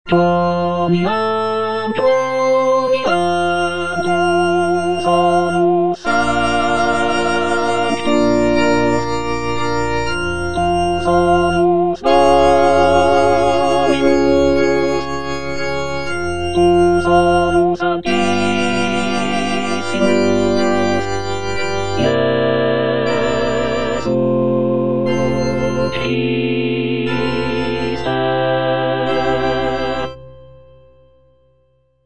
(All voices)
choral work